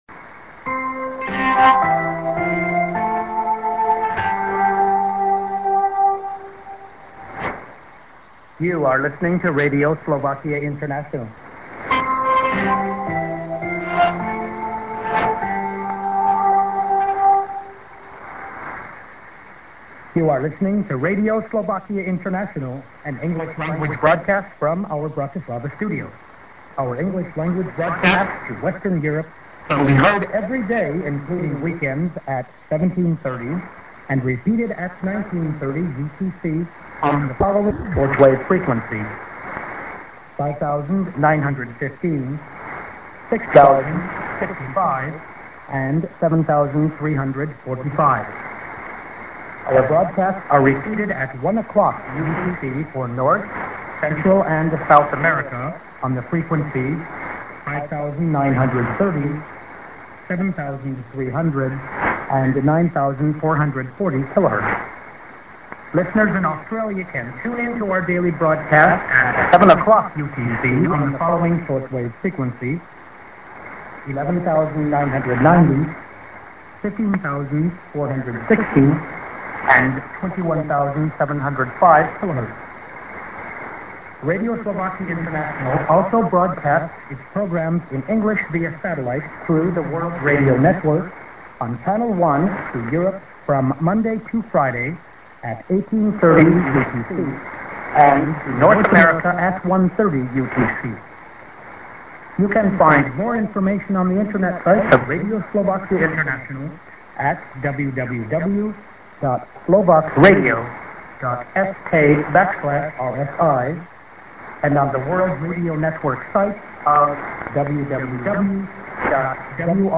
a:　IS-ID+SKJ+WebADDR(man)->IS